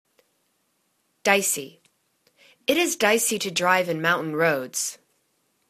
dic.ey     /'di:si/    adj